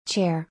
・「R」の発音がアメリカ英語の方が強く発音する傾向があります。
chair_a.mp3